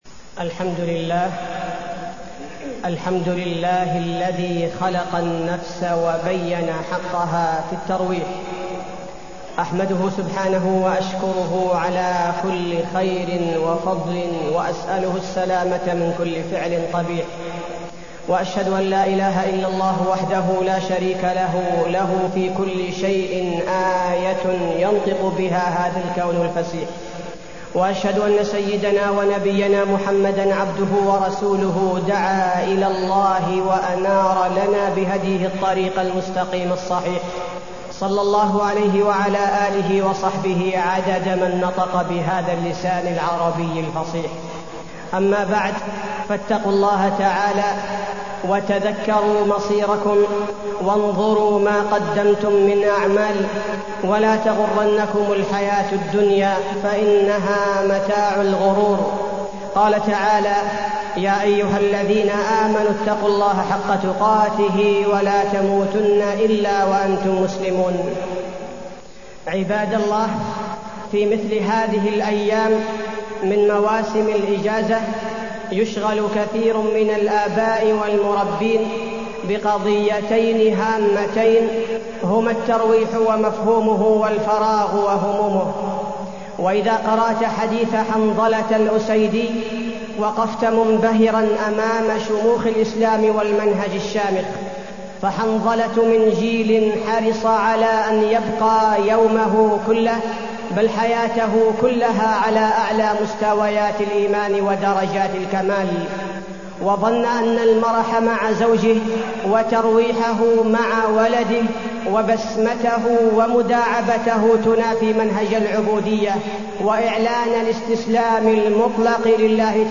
تاريخ النشر ١٦ ربيع الأول ١٤٢٢ هـ المكان: المسجد النبوي الشيخ: فضيلة الشيخ عبدالباري الثبيتي فضيلة الشيخ عبدالباري الثبيتي الترويح عن النفس وضوابطه